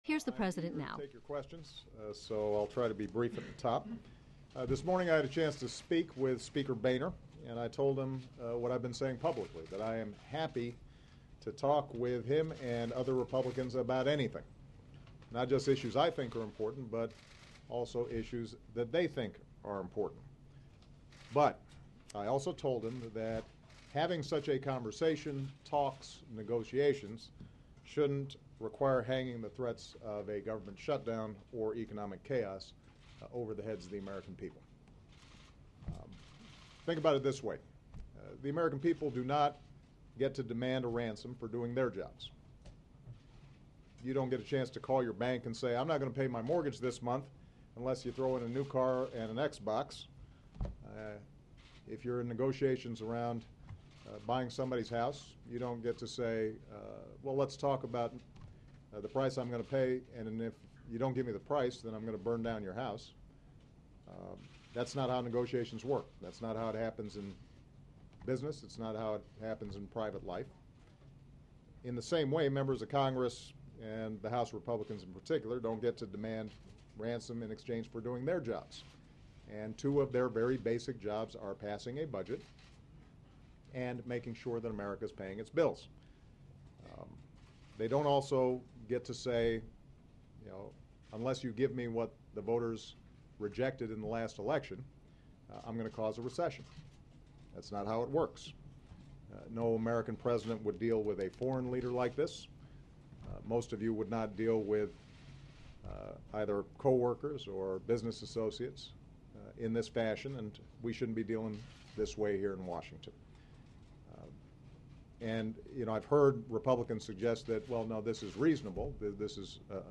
President Obama said he's willing to talk about anything, as long as Republicans reopen the government and raise the debt ceiling. He took questions for more than an hour about the standoff, which has led to a partial government shutdown and has now transitioned into a fight over the debt ceiling.